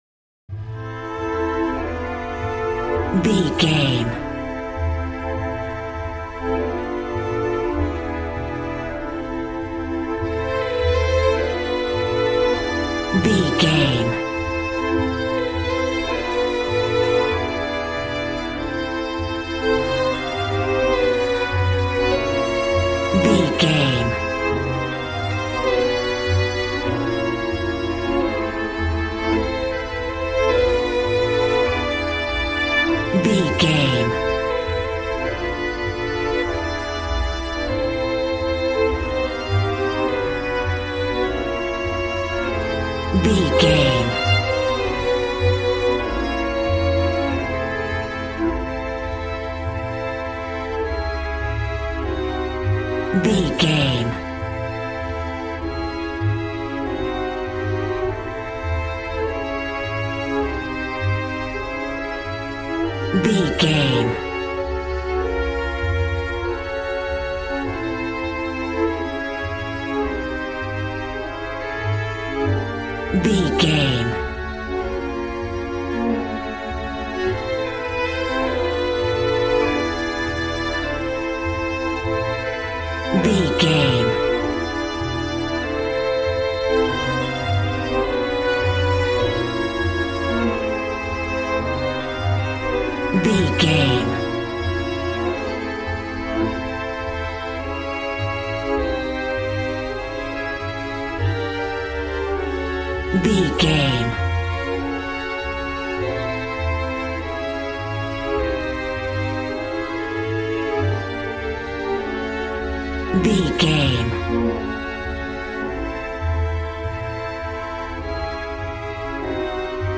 Aeolian/Minor
dramatic
epic
percussion
violin
cello